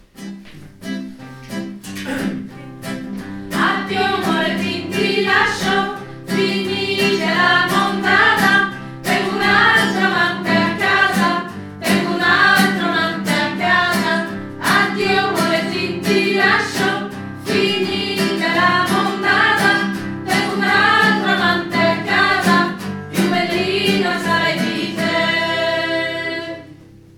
Addio_Morettin_Soprane.mp3